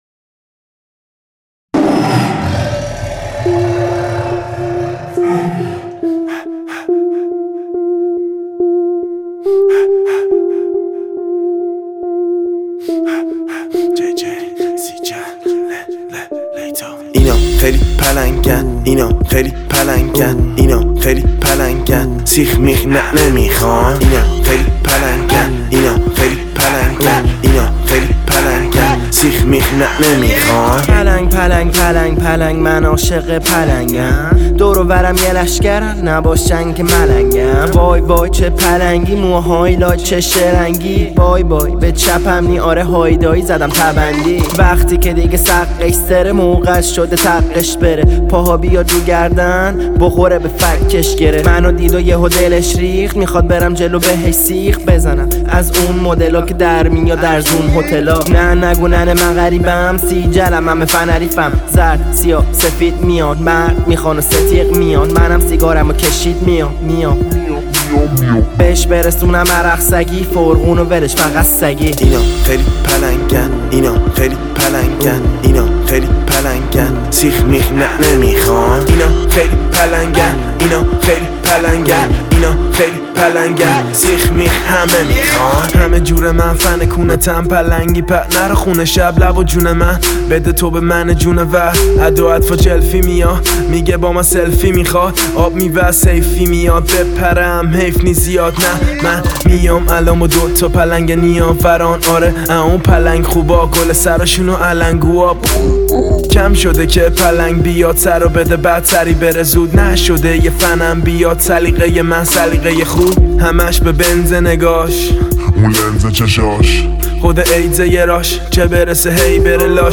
Sing: Hip Hop - آواز: هیپ ‌هاپ